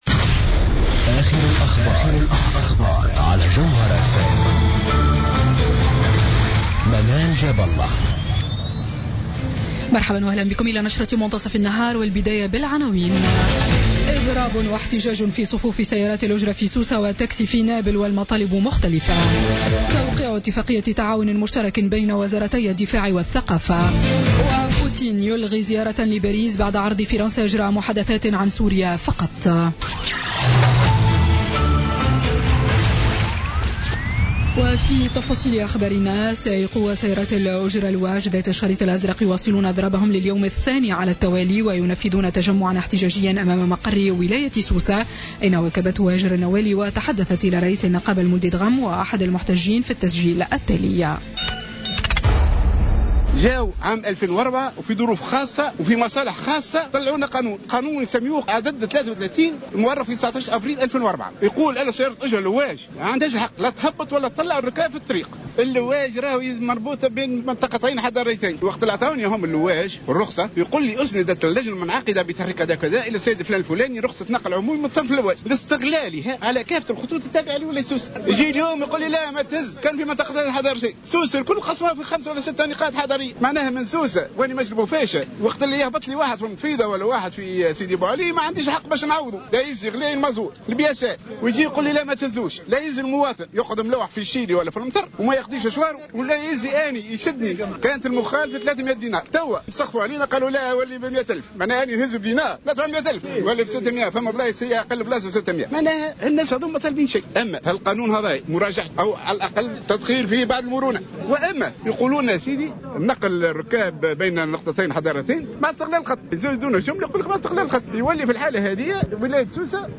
نشرة أخبار منتصف النهار ليوم الثلاثاء 11 أكتوبر 2016